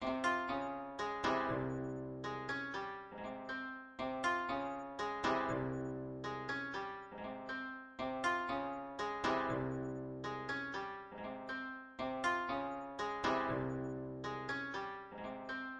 koto（筝）是一种传统的日本弦乐器
描述：koto（筝）是一种传统的日本弦乐器。录制在Cubase中。
标签： groove 原声 弦乐 音乐 影片 传统 视频 艺妓 日本 节奏 东部 武士 亚洲 古筝 电影 将军 循环
声道立体声